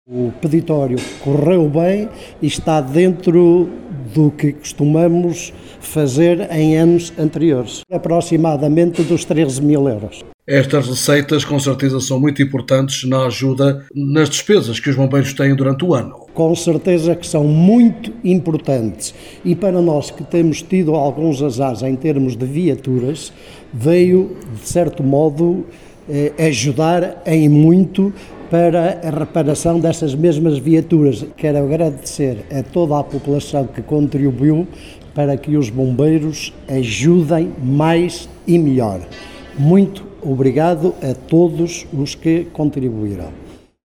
em declarações à Alive FM, fez o balanço deste peditório e agradeceu a todos (as) que colaboraram nesta iniciativa.